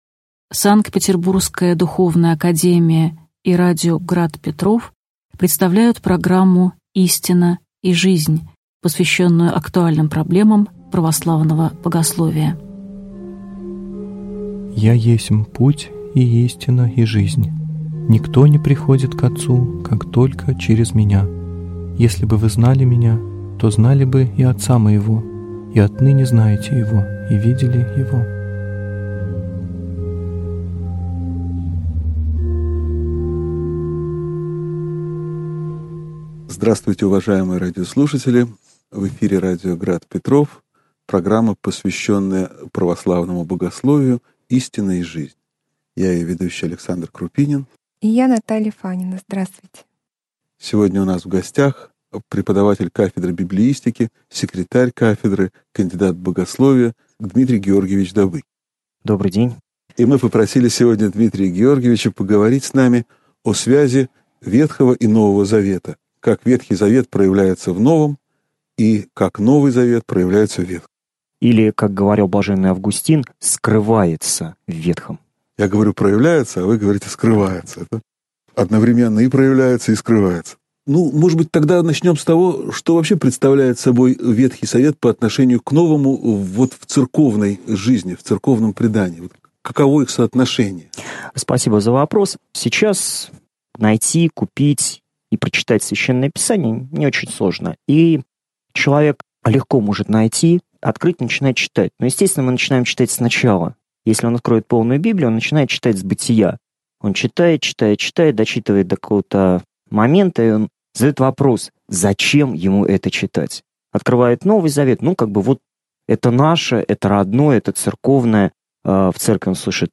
Аудиокнига Единство Ветхого и Нового Заветов (часть 1) | Библиотека аудиокниг